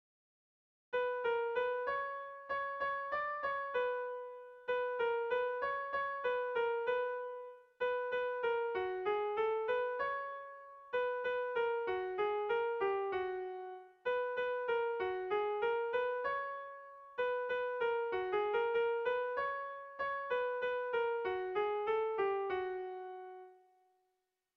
Air de bertsos - Voir fiche   Pour savoir plus sur cette section
Haurrentzakoa
ABD1D2D1D2